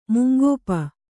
♪ mungōpa